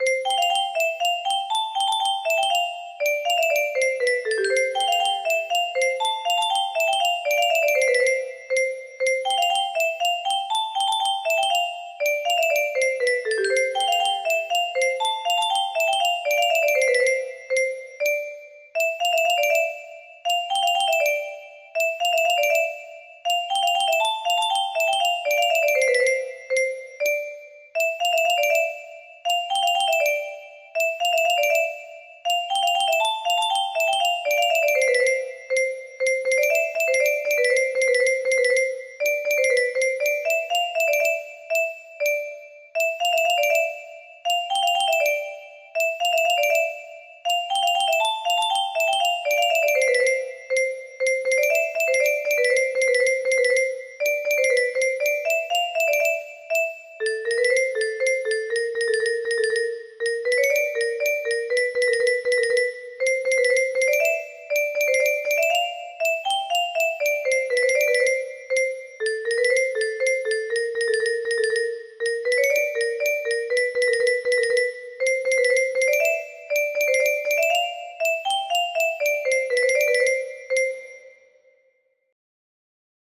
Polonäise - Dahlhoff 2.39 music box melody
Grand Illusions 30 (F scale)